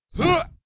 guard_attack5.wav